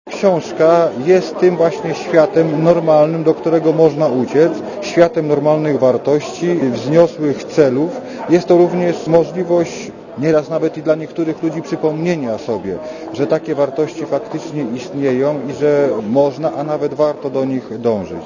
Komentarz audio